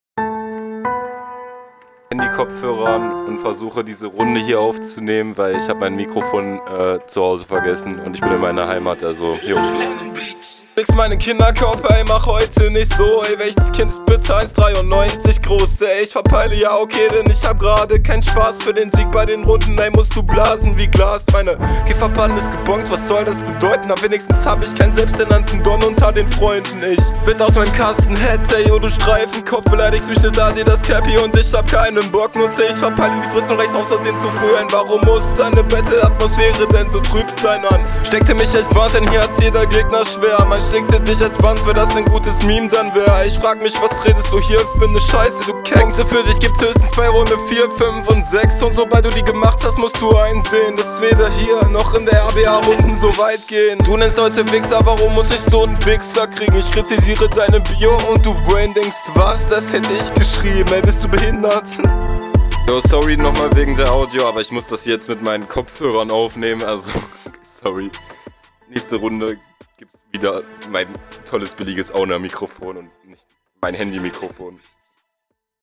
Kein richtiges Mikro...
Ok:D Die Runde ist improvisiert.. ich glaube da muss gar nicht soviel gesagt werden.